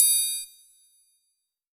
HOTRIANG.wav